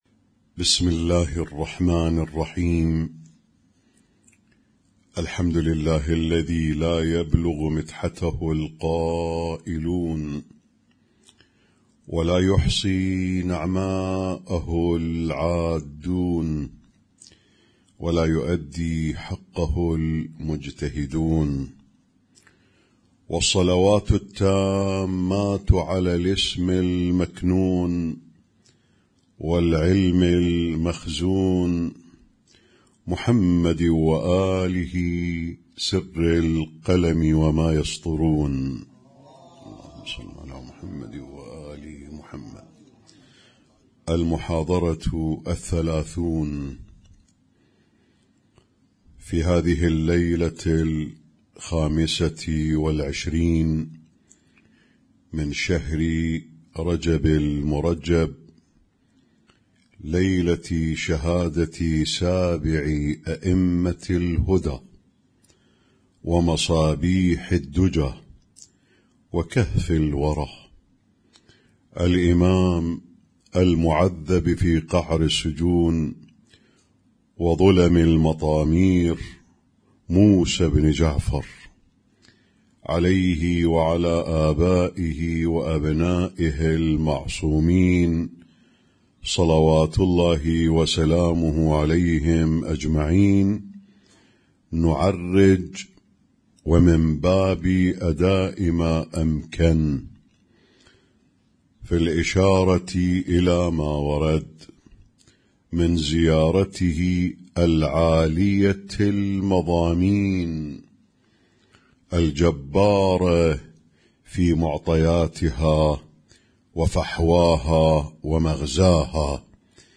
Husainyt Alnoor Rumaithiya Kuwait
اسم التصنيف: المـكتبة الصــوتيه >> الدروس الصوتية >> الرؤية المعرفية الهادفة